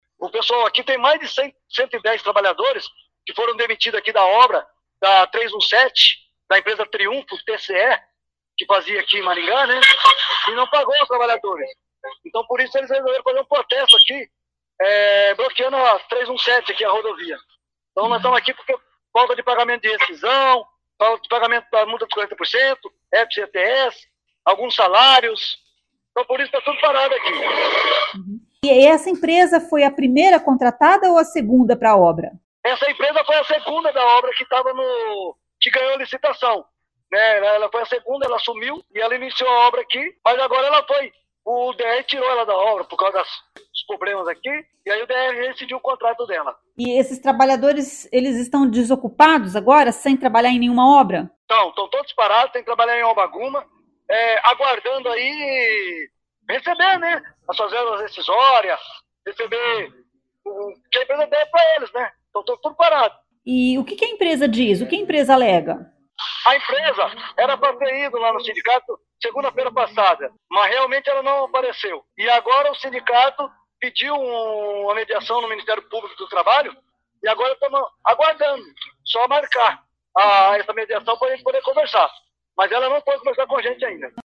A CBN tenta contato com a empresa citada na entrevista.